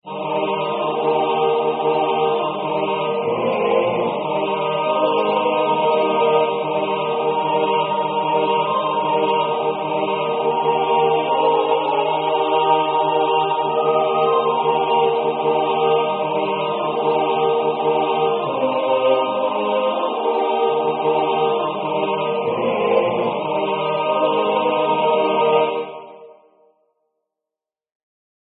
Language: English Instruments: Keyboard
First published: 1905 in Soul Echoes, Edition 1, no. 0 2nd published: 1909 in Soul Echoes, Edition 2, no. 2 3rd published: 1916 in New Songs of Paradise, Edition 1, no. 2 Description: A gospel hymn.